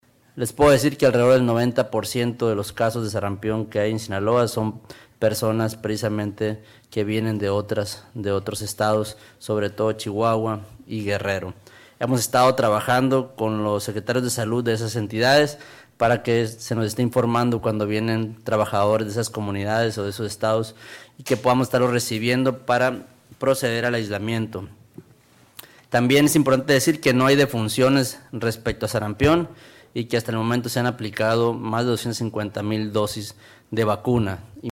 Culiacán, Sinaloa; 26 de enero de 2026.- Durante la conferencia semanera del gobernador Dr. Rubén Rocha Moya, el secretario de Salud, Dr. Cuitláhuac González Galindo, informó que en Sinaloa no se han registrado defunciones por sarampión, pese al incremento de casos en la entidad.